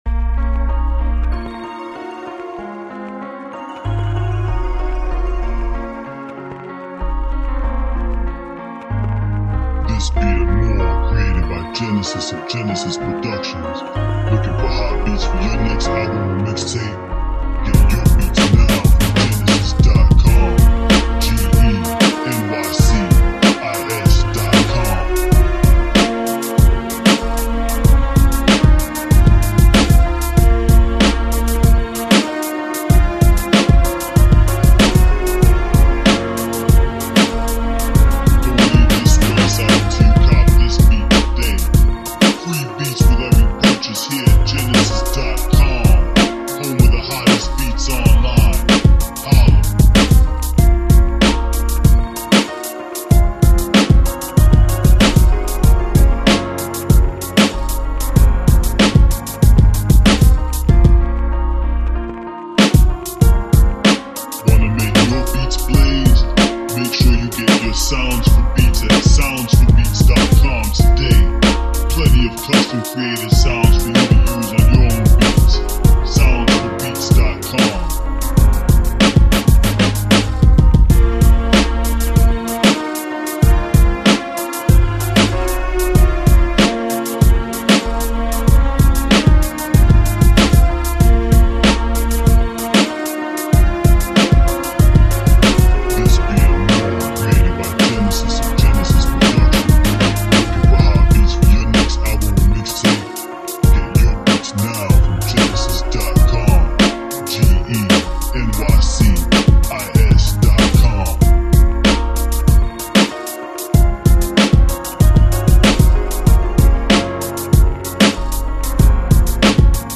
Piano Flava Hip Hop Beat